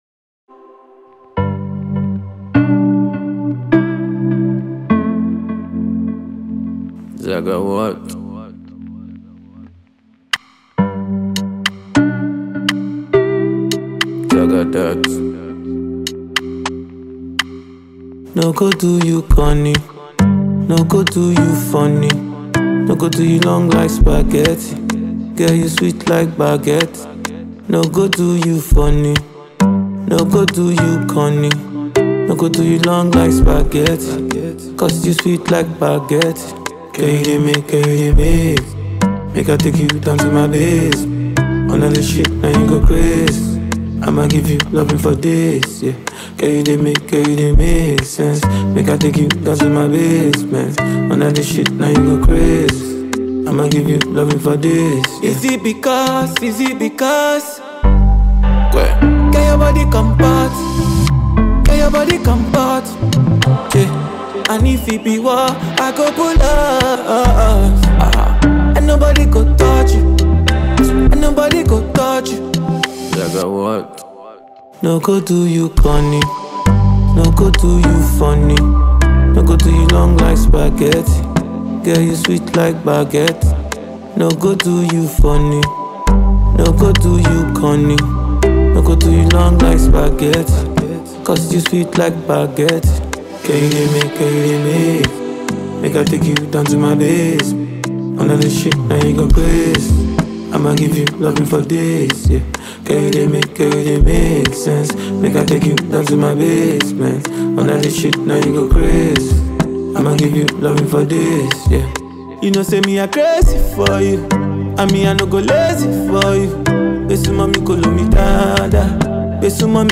This one is infused with Hip-hop sound and it bangs hard.